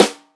• Studio Steel Snare Drum Sound D Key 58.wav
Royality free snare single hit tuned to the D note. Loudest frequency: 2231Hz
studio-steel-snare-drum-sound-d-key-58-2Os.wav